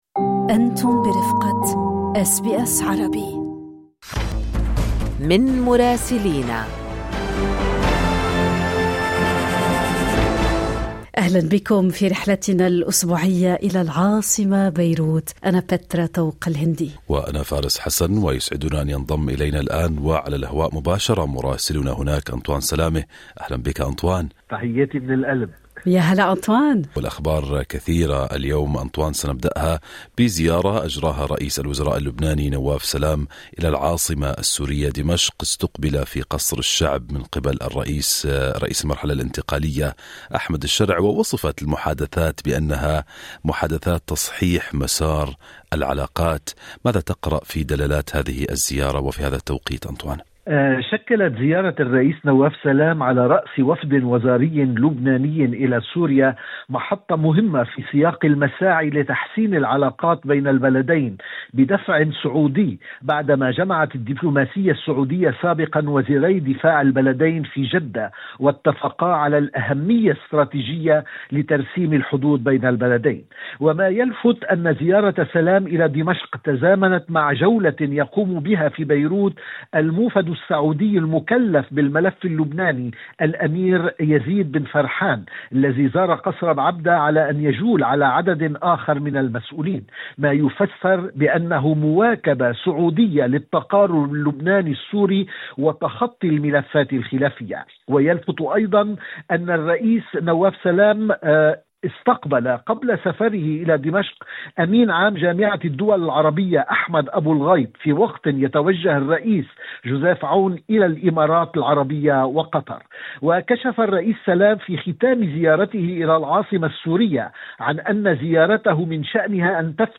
رئيس الوزراء اللبناني في دمشق للمرة الأولى وأخبار أخرى مع مراسلنا في بيروت